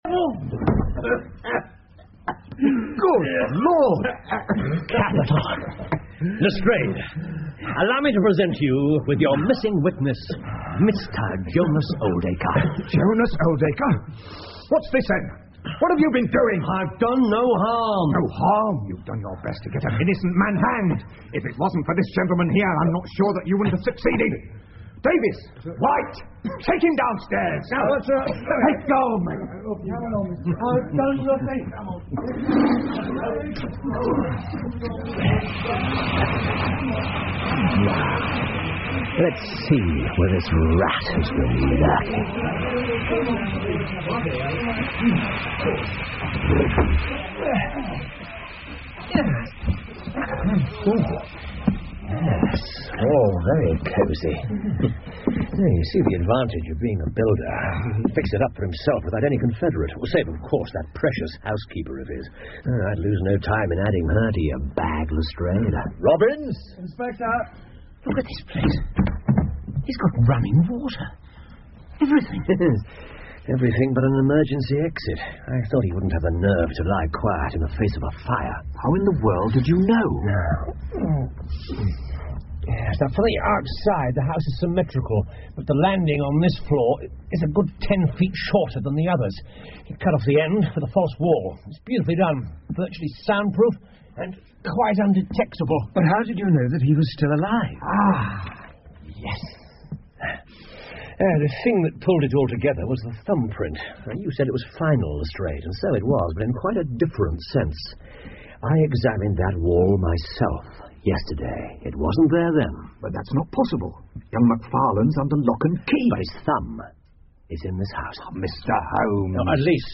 福尔摩斯广播剧 The Norwood Builder 8 听力文件下载—在线英语听力室